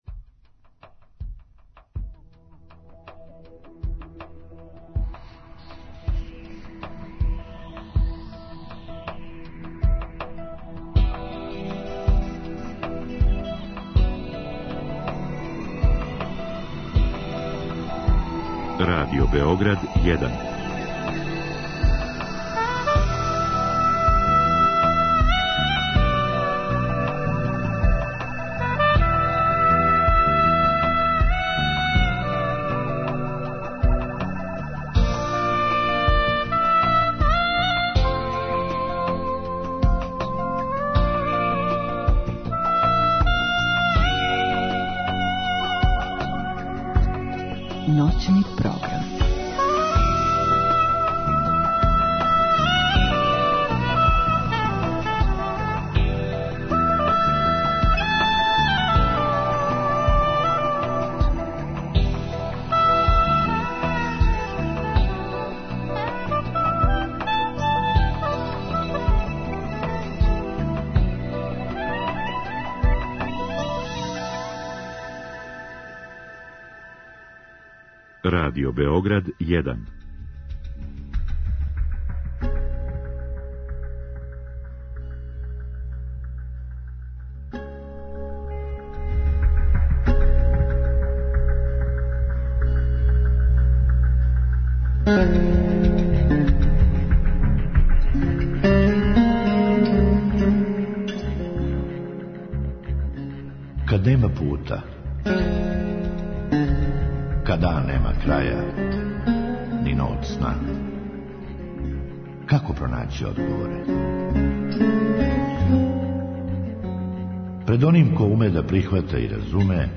У другом сату емисије слушаоци могу директно да се укључе у програм са својим коментарима, предлозима и проблемима који се односе на психолошки свет, међу свим другим световима.